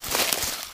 STEPS Bush, Walk 11.wav